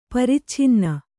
♪ paricchinna